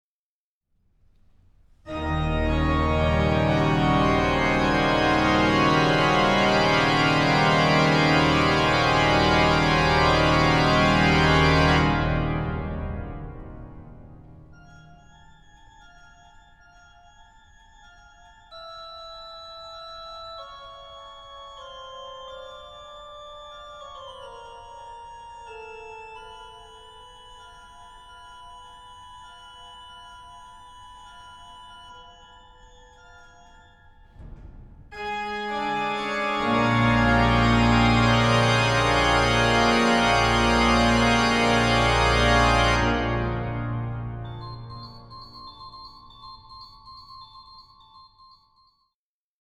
Konzertmitschnitt vom 27.01.2010
St. Magareta, Düsseldorf
Unichor Düsseldorf